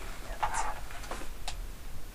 Two different voices were recorded in this clip, both seemingly talking at almost the same time. One appears to be a male whisper, the second is a female voice that begins to speak as the first voice whispers.
CD Audio Quality I hear a soft, but clear male whisper saying, "Get out."